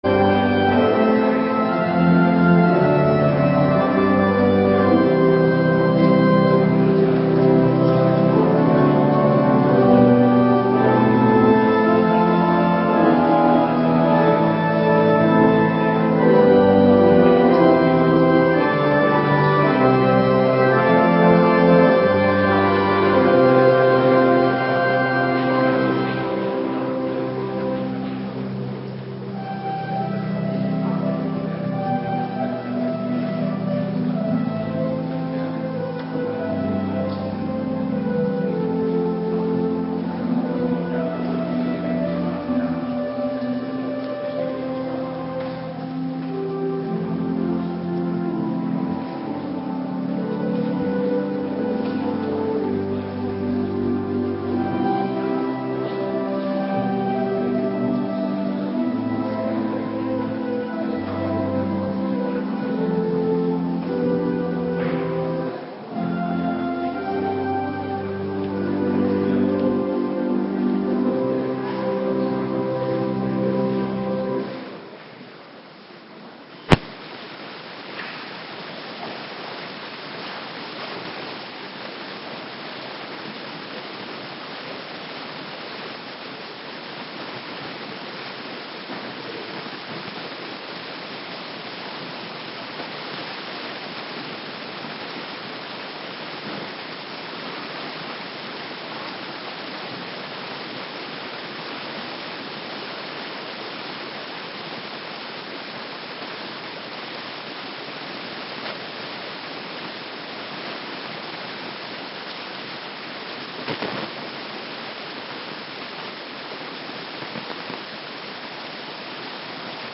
Avonddienst Voorbereiding Heilig Avondmaal
Locatie: Hervormde Gemeente Waarder